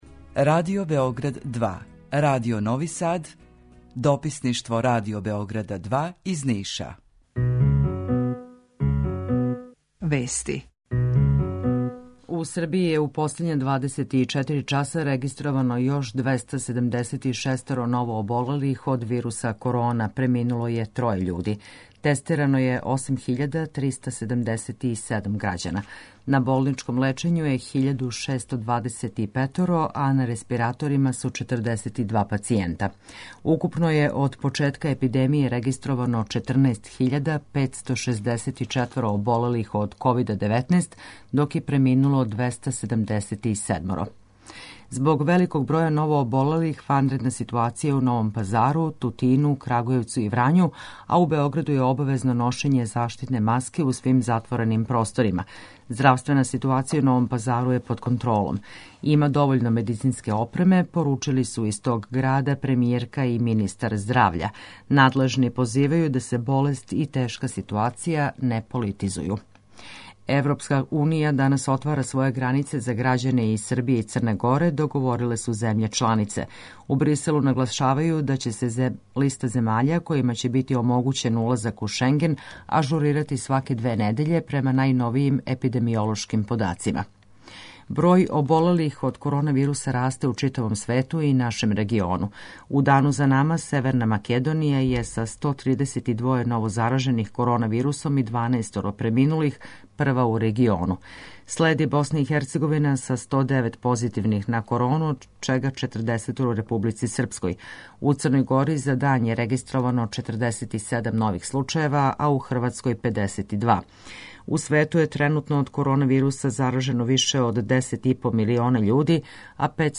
Укључење Радио Грачанице
Јутарњи програм из три студија